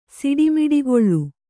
♪ siḍi miḍigoḷḷu